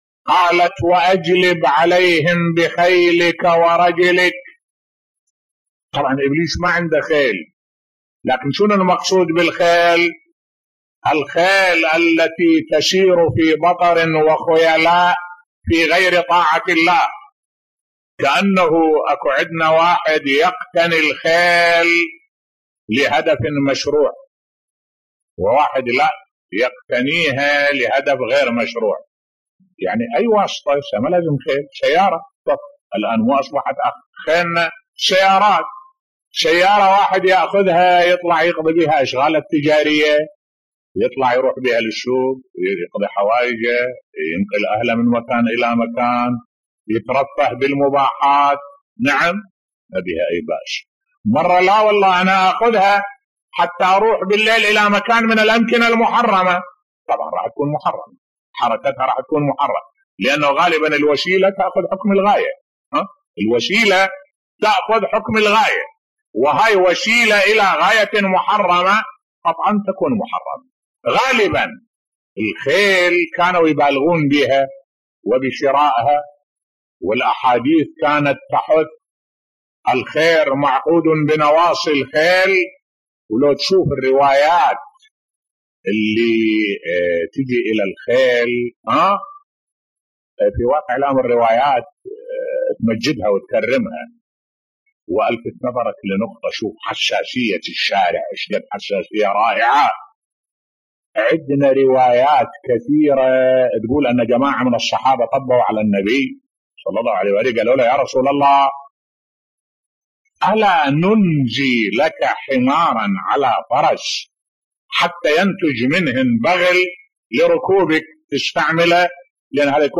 ملف صوتی أهمية الخيل عند العرب و المسلمين بصوت الشيخ الدكتور أحمد الوائلي